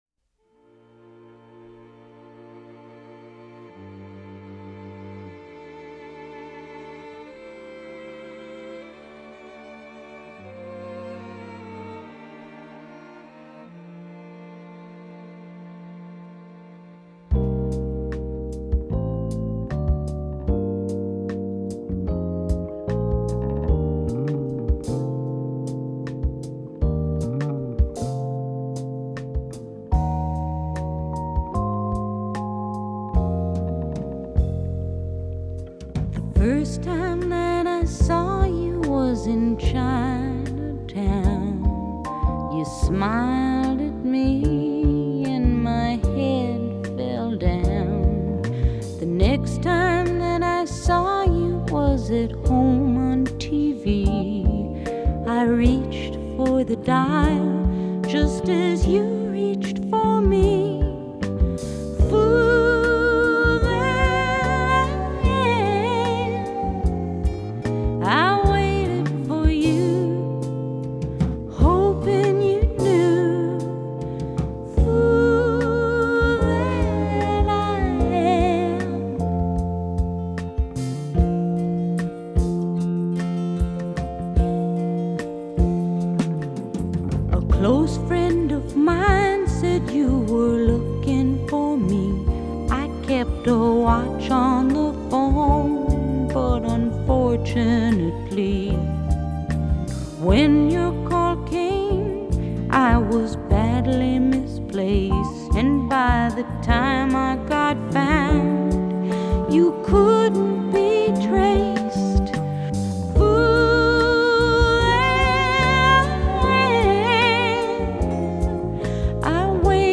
Genre: Pop, Folk, Rock